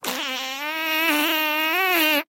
Отец сидит на диване и отдыхает